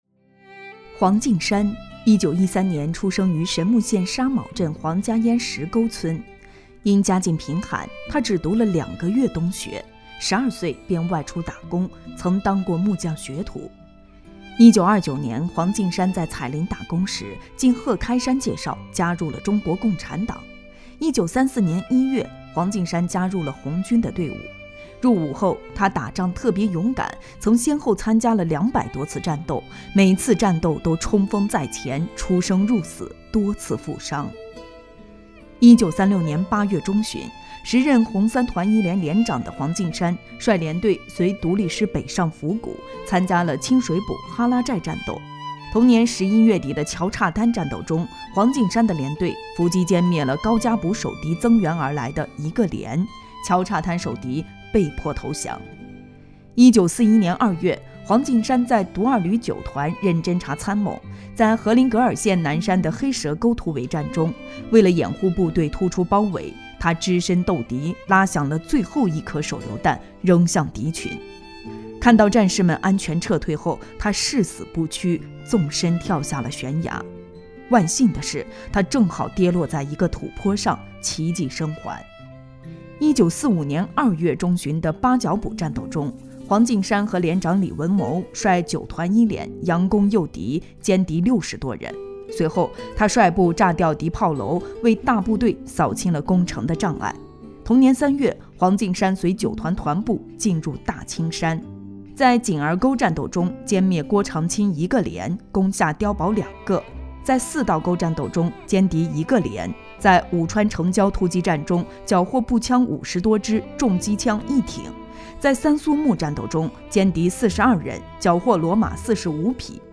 【红色档案诵读展播】共产党员黄进山